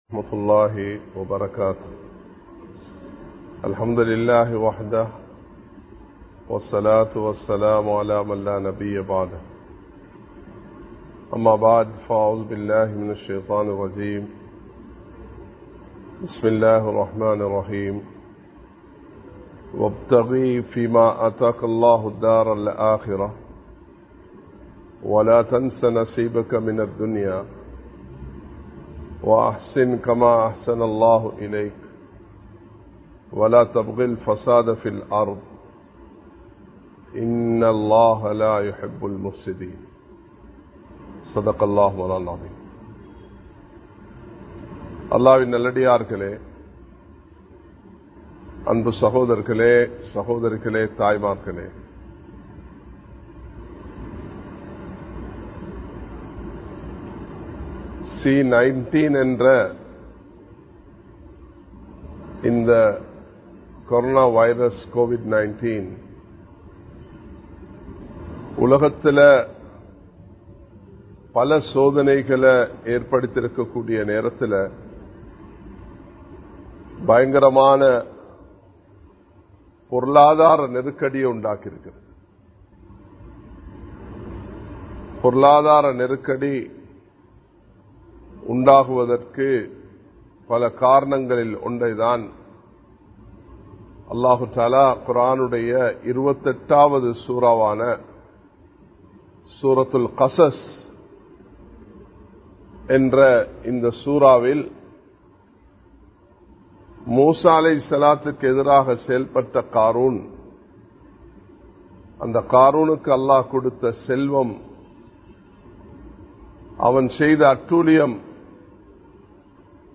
கொவிட் 19 & பொருளாதாரம் (Covid 19 & Economy) | Audio Bayans | All Ceylon Muslim Youth Community | Addalaichenai
Live Stream